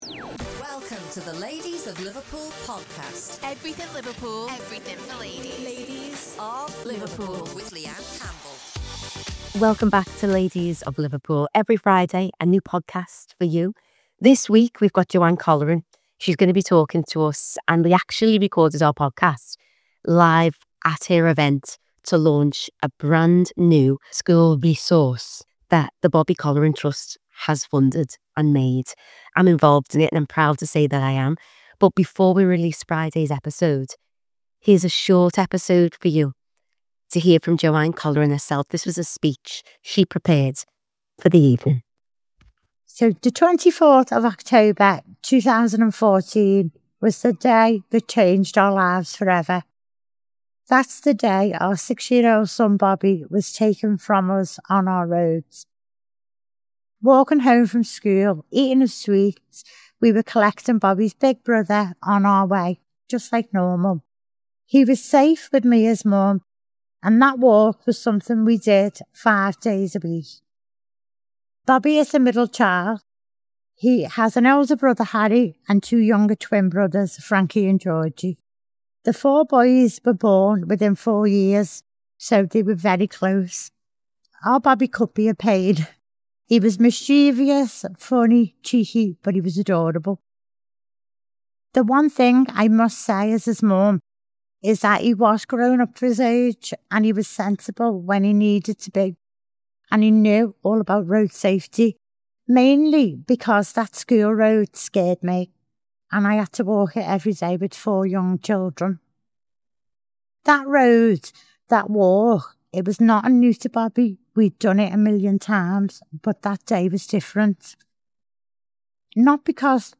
reading the speech she prepared for the launch